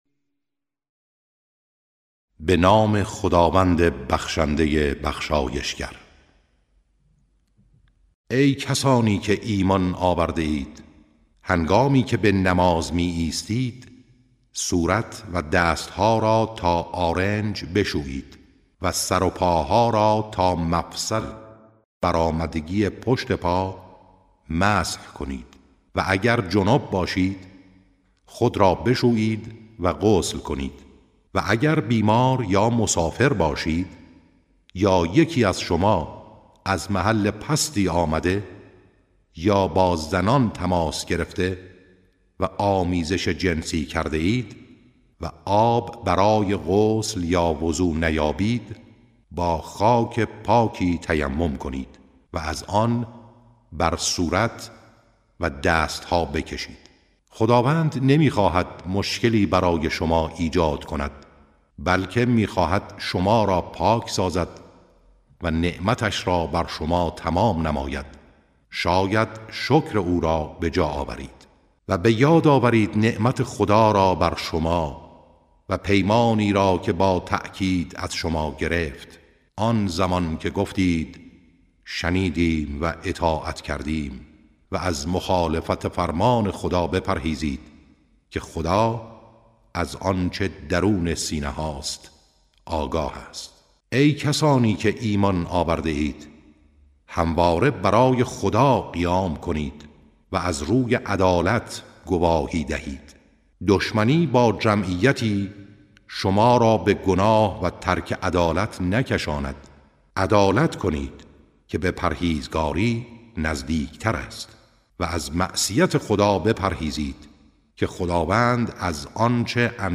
ترتیل صفحه 108 از سوره مائده(جزء ششم)
ترتیل سوره (مائده)